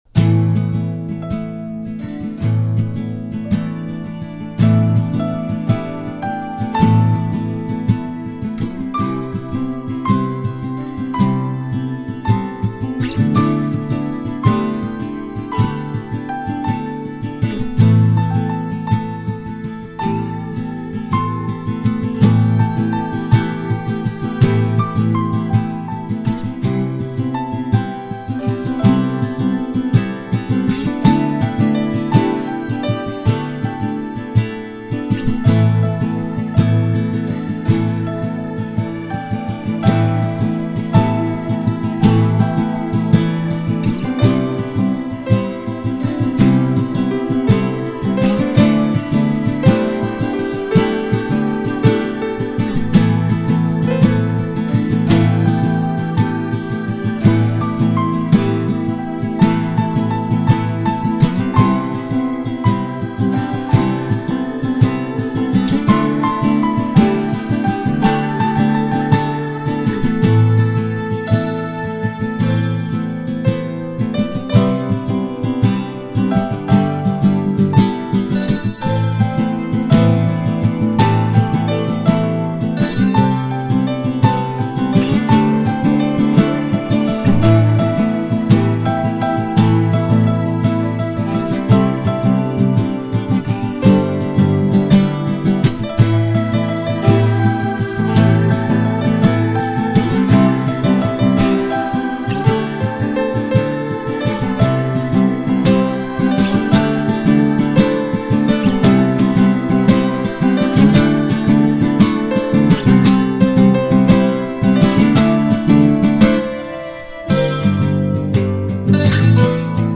Style: FOLK & JAZZ
Hintergrund Musik für gemütliche Locations.